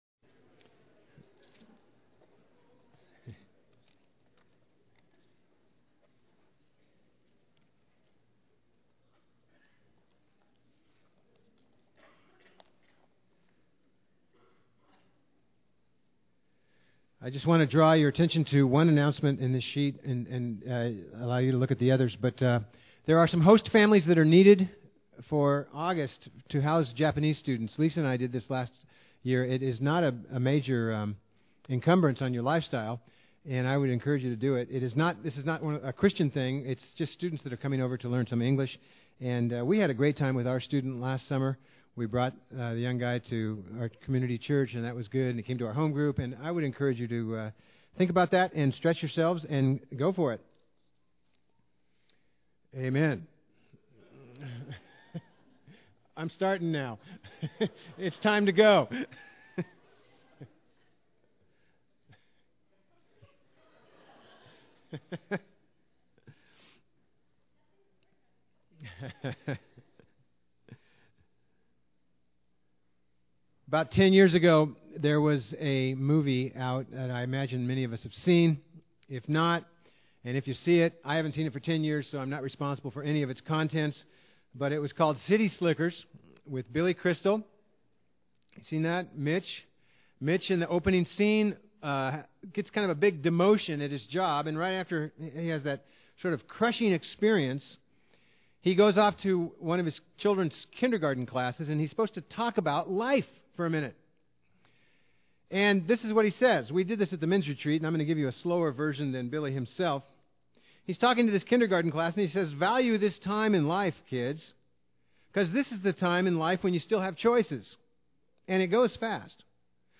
Proverbs Service Type: Sunday Preacher